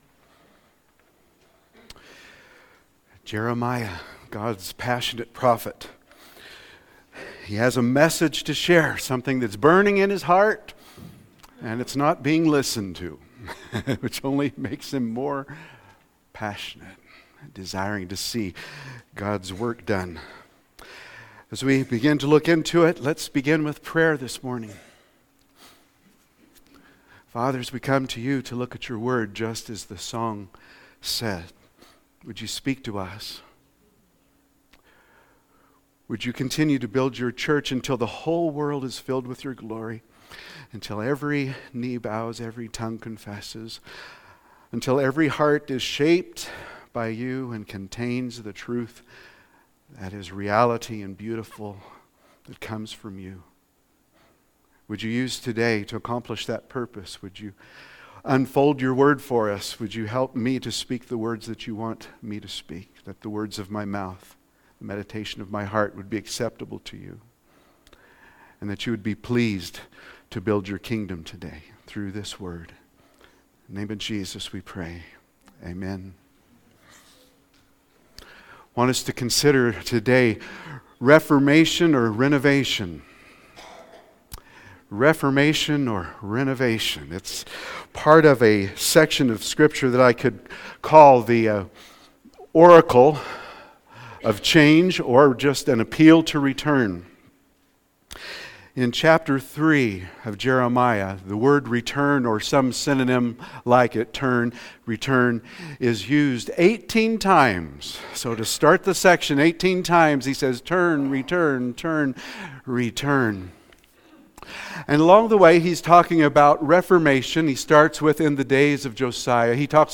The reforms of man do not accomplish the renovation of the heart. Continuing the series: JEREMIAH : God’s Passionate Prophet 2-16-25 Notes – Reformation or Renovation (Note: Due to technical issues with our streaming provider, a video recording of this sermon is not currently available.)